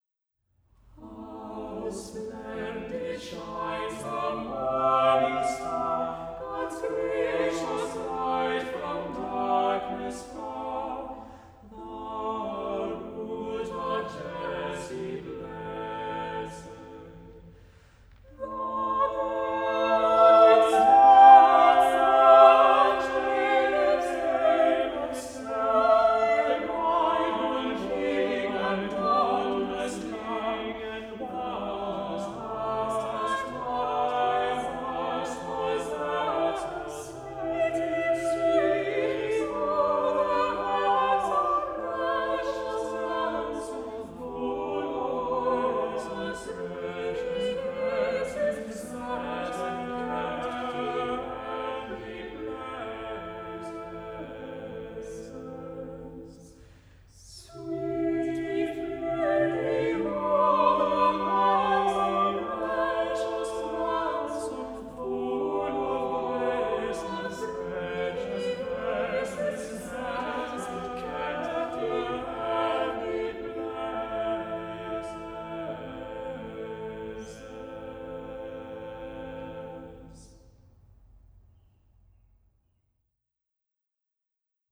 soprano
Choir & Chamber Works: